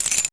1 channel
PICKKEY.WAV